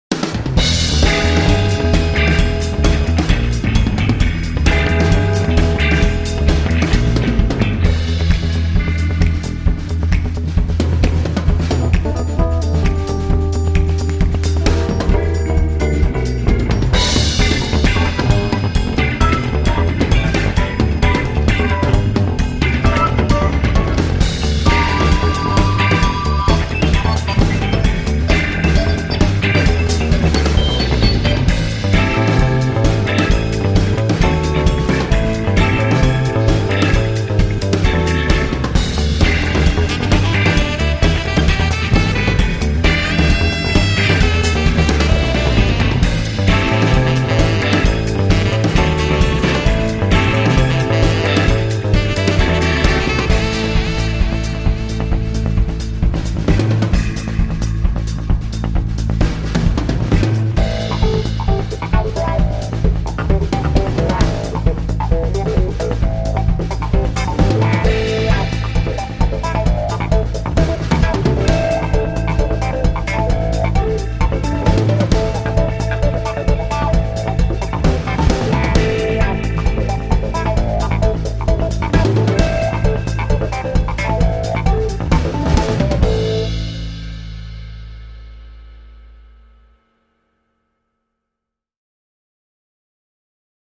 basse électrique, Fender Rhodes